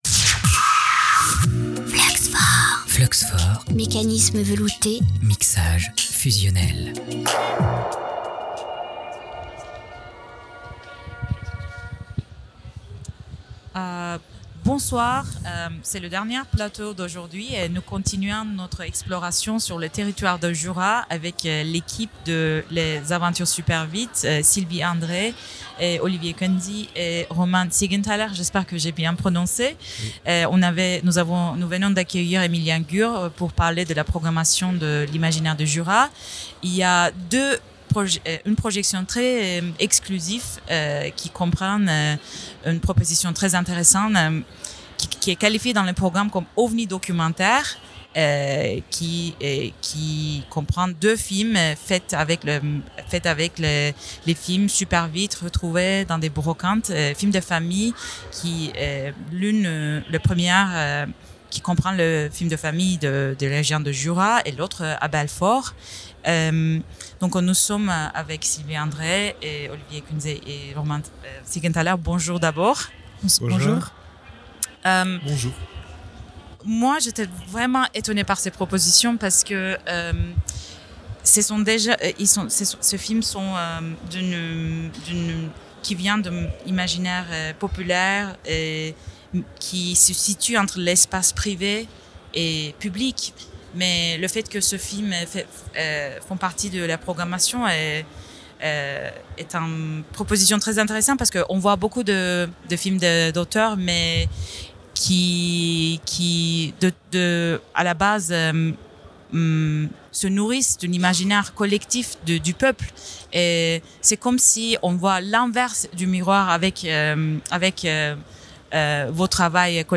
FLUX4 RADIO, la webradio offshore sans publicité et sans talkshow